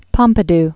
(pŏmpĭ-d, pôɴ-pē-d), Georges Jean Raymond 1911-1974.